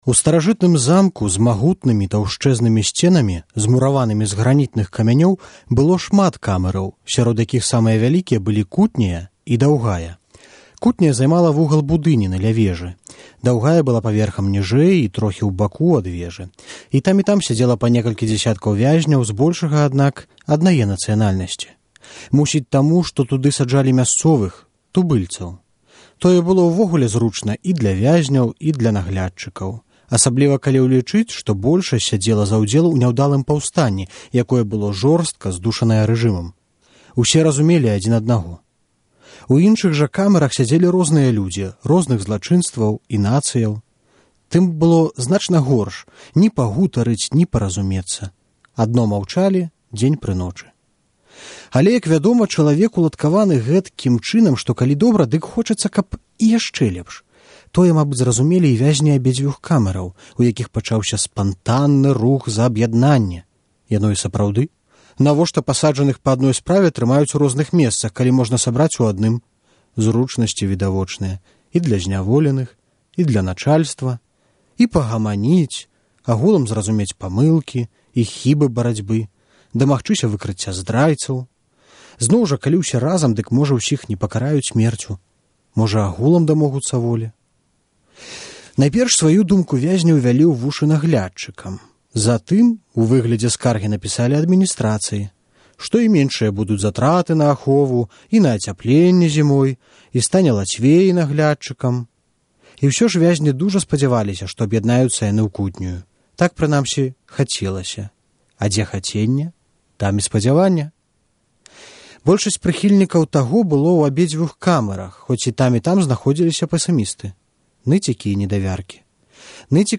чытае "Аб'яднаньне" Васіля Быкава